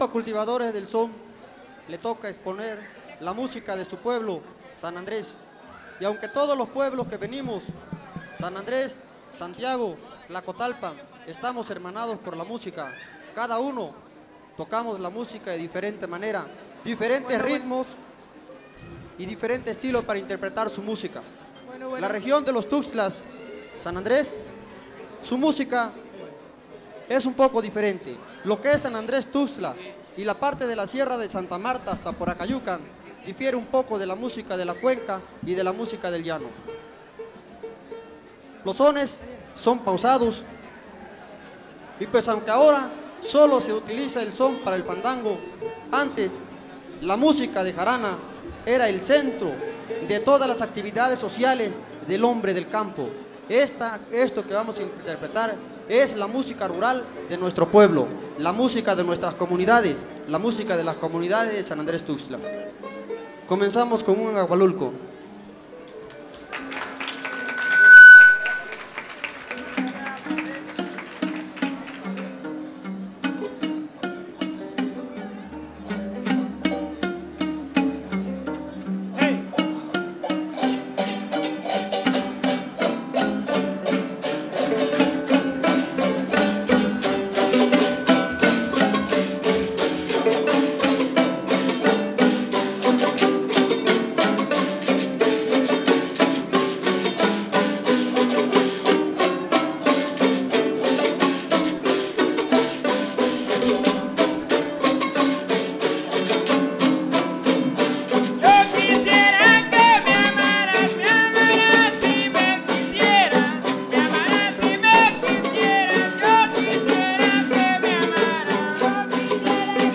• Cultivadores del son (Grupo musical)
Sexto Encuentro de Etnomusicología. Fandango de clausura